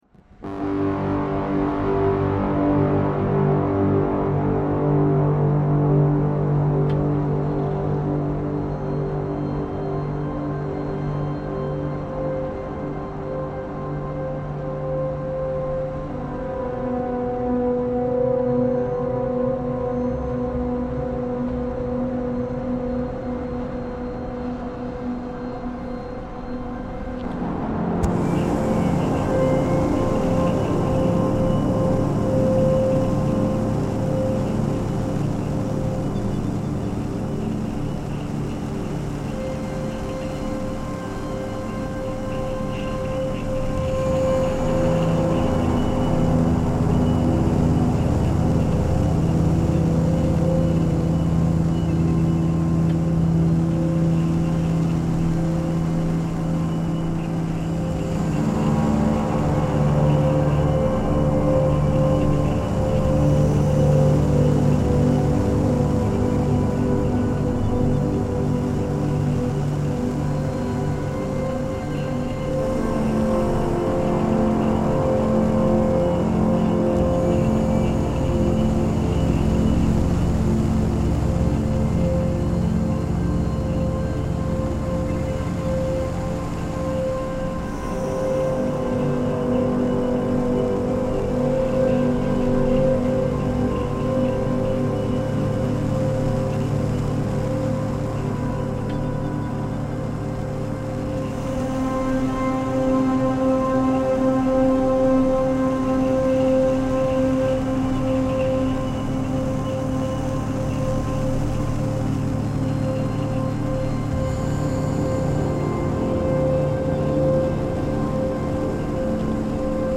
Liquid nitrogen tanks at Oxford University reimagined